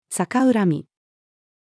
逆恨み-female.mp3